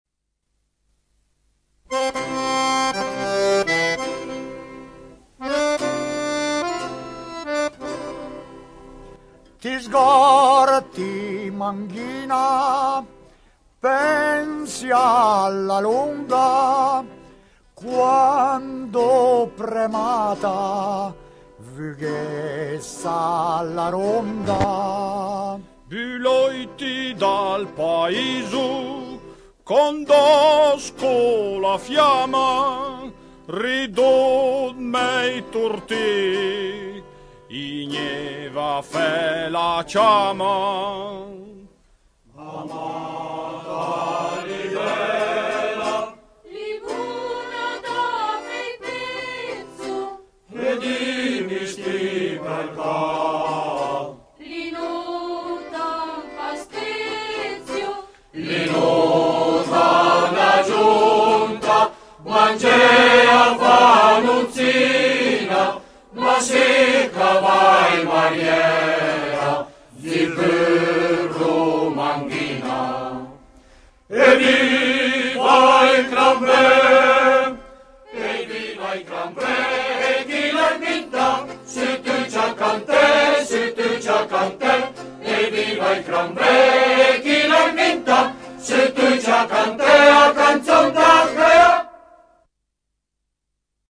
canzone
chitarra
fisarmonica